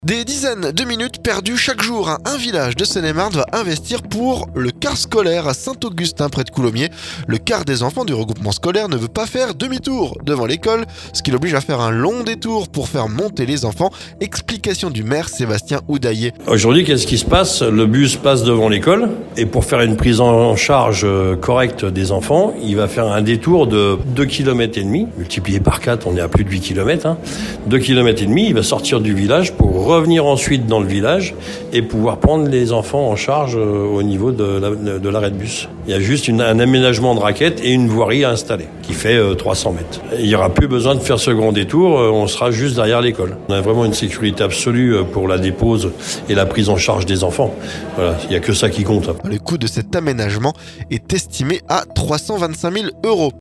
Explications du maire Sébastien Houdayer.